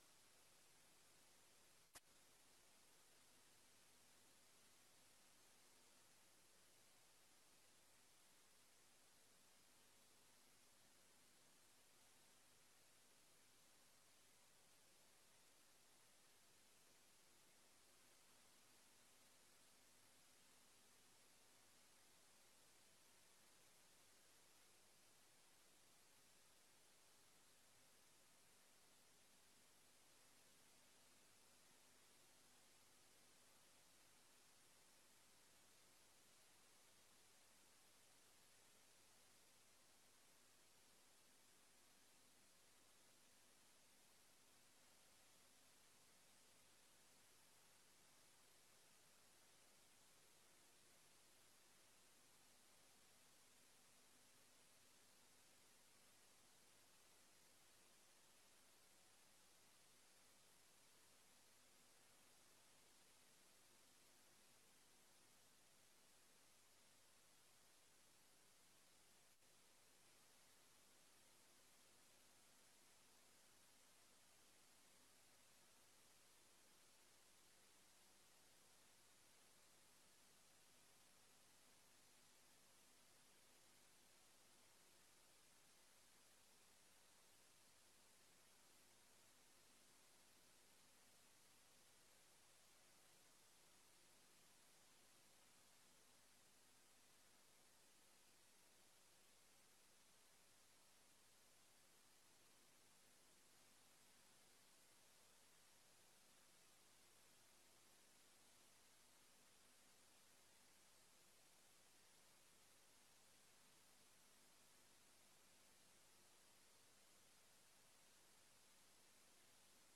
Openbare zitting Centraal Stembureau